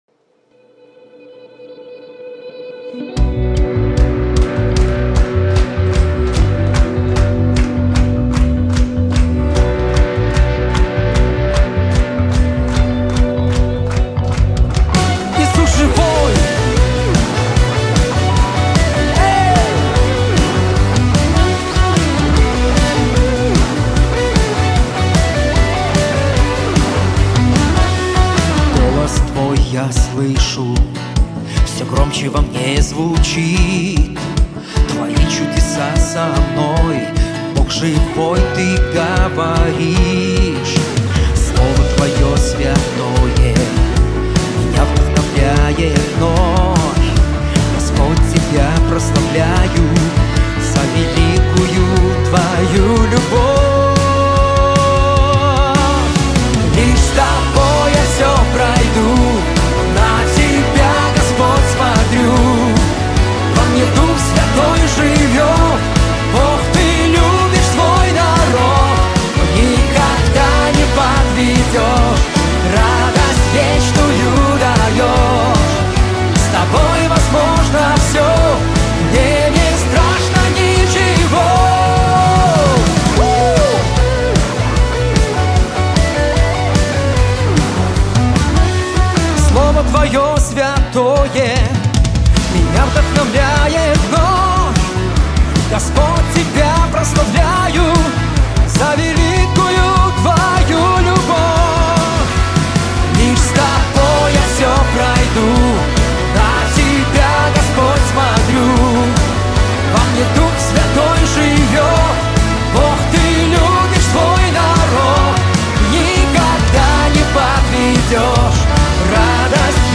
139 просмотров 206 прослушиваний 10 скачиваний BPM: 150